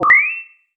sci-fi_power_up_11.wav